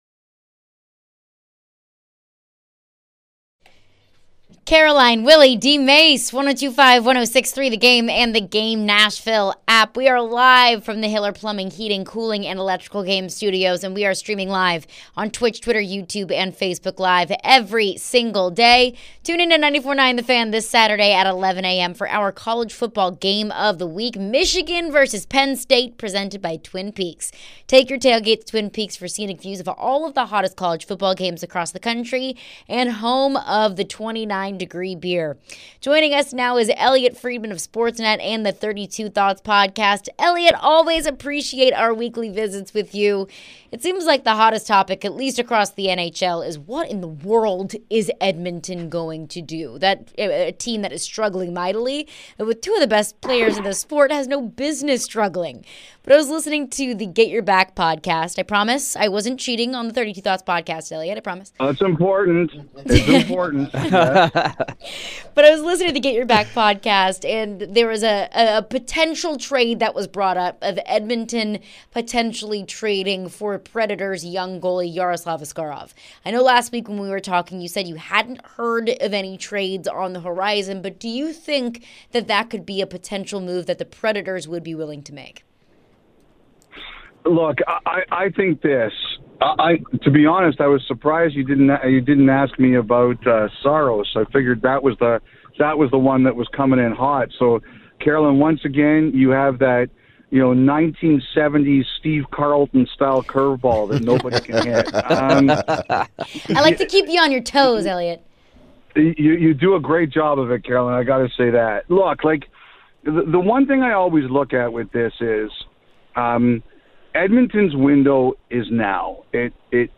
the guys talk with Elliotte Friedman with Sportsnet Hockey Night in Canada. Elliotte discusses the Nashville Predators and other news around the league. Elliotte mentioned the possibility of trade talks involving Juuse Saros.